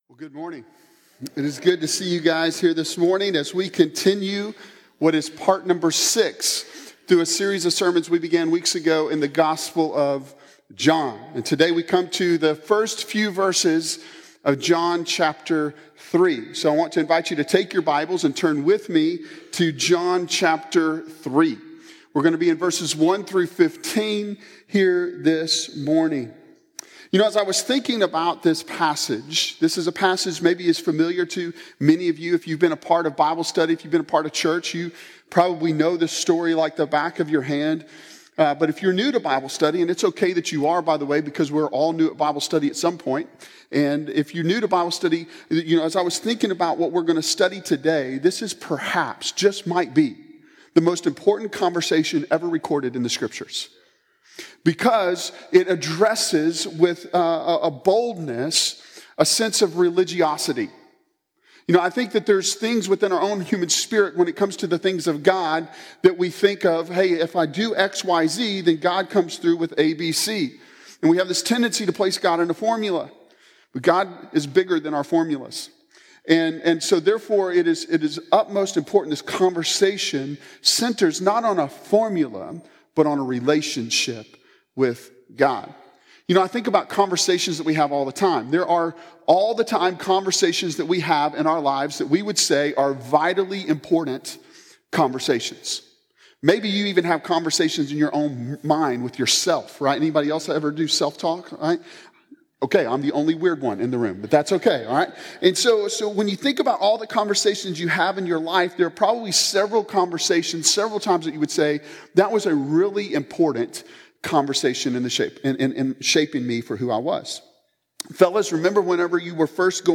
Series: The Gospel of John Sermon Series